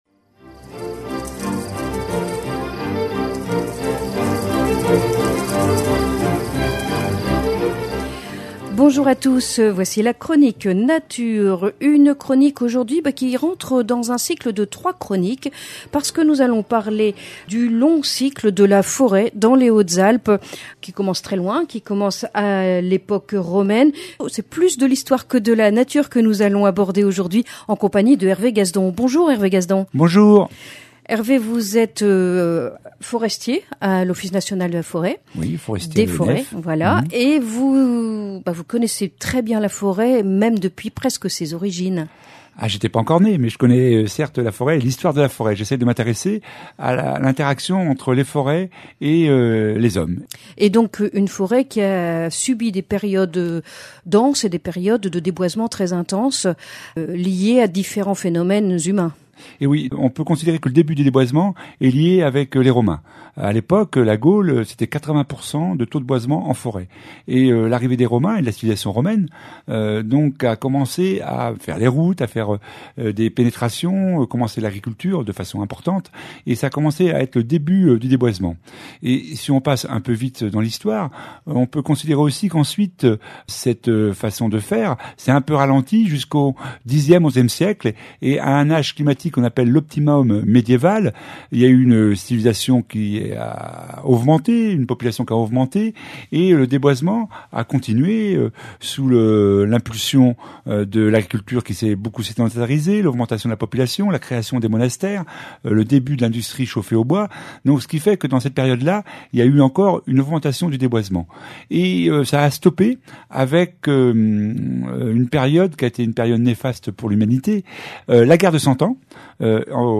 Chronique nature L' histoire de la forêt est liée à celle de l'homme.